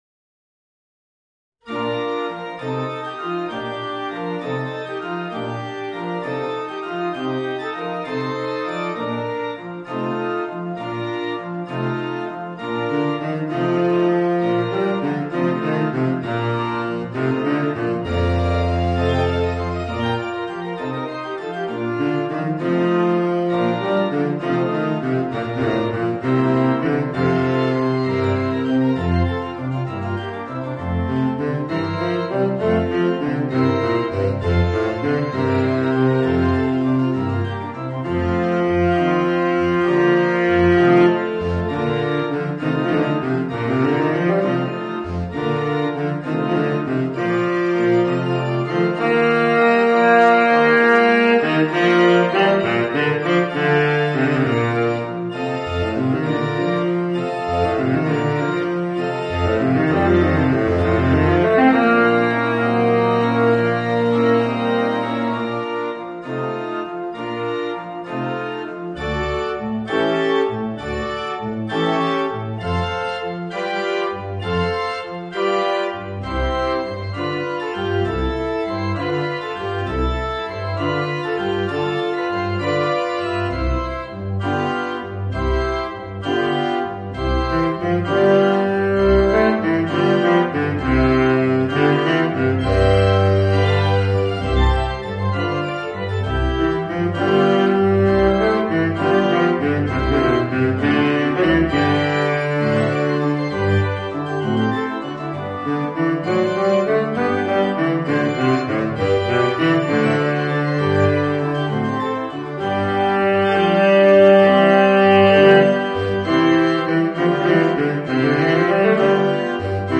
Voicing: Baritone Saxophone and Organ